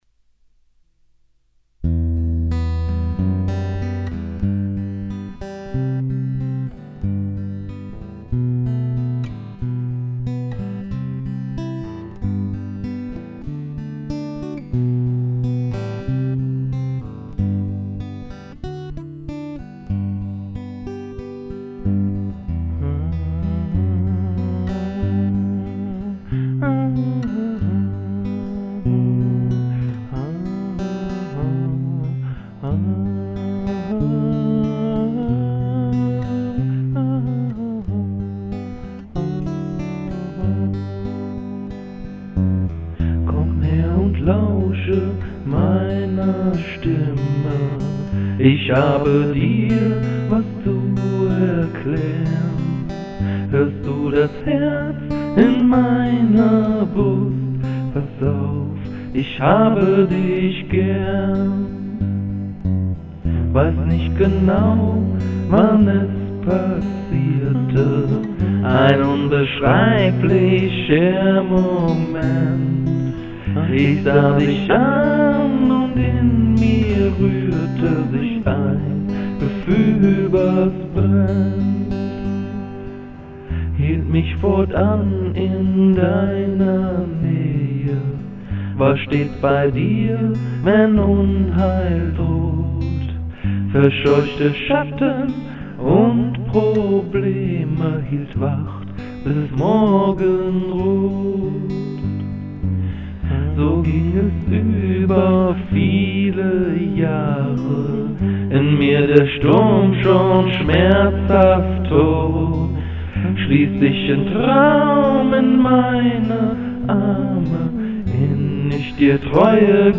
Ein guter lieber Freund hat mir dazu die Gitarre gespielt